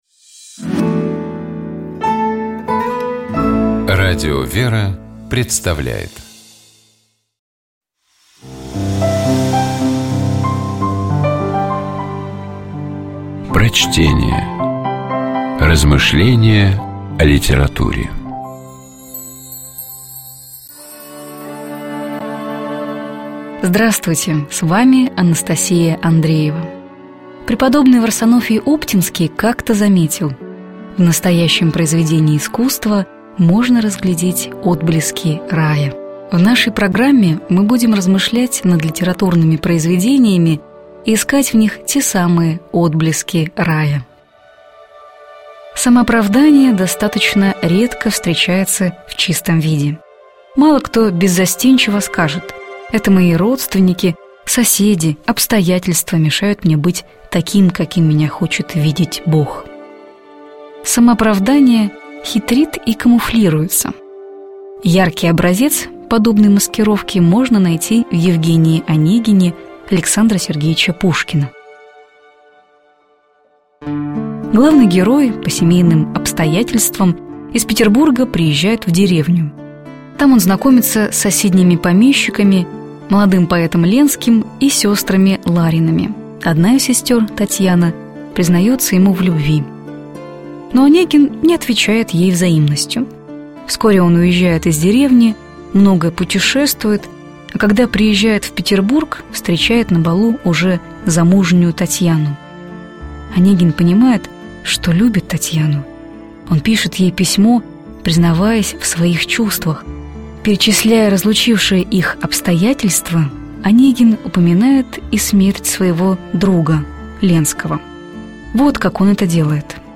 Prochtenie-Aleksandr-Pushkin-Evgenij-Onegin-Iskushenie-samoopravdanija.mp3